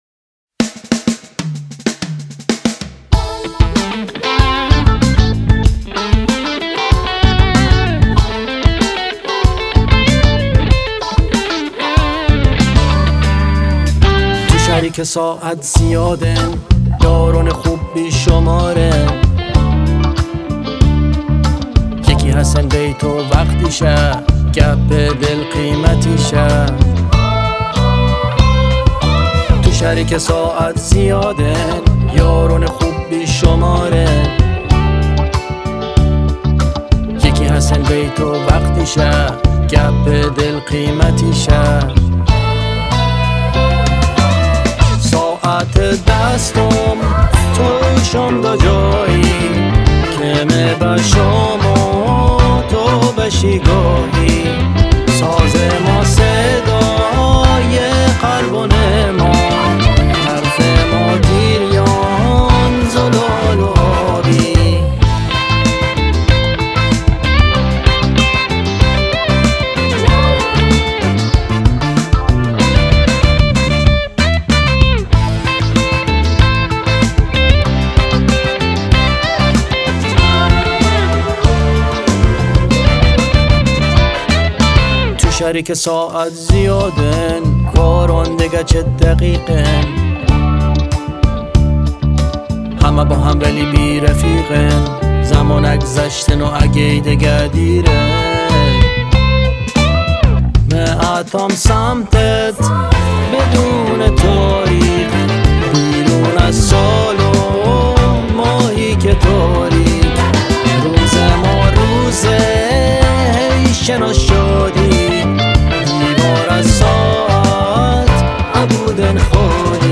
🎸 گیتار الکتریک
🎸 گیتار باس